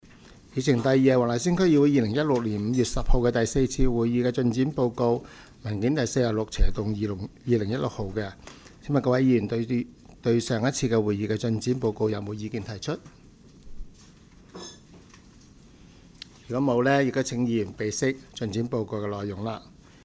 区议会大会的录音记录
黄大仙区议会第五次会议